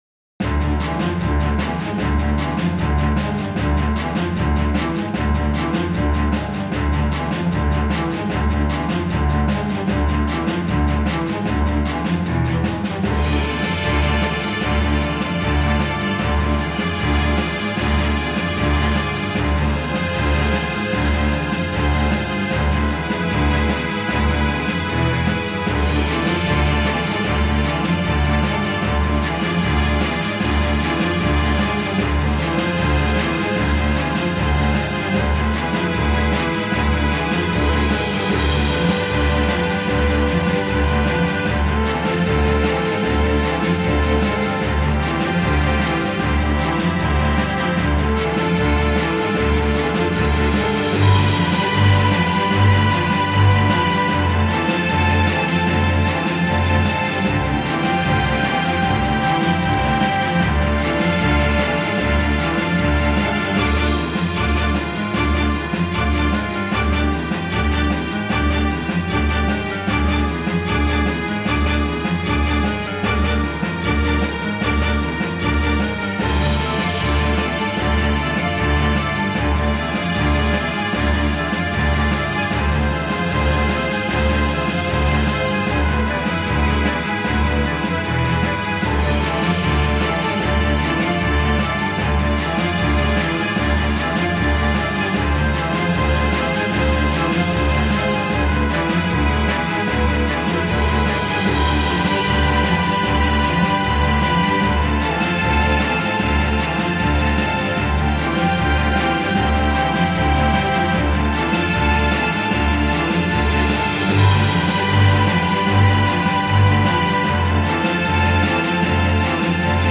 Небольшая пьеса для струнных.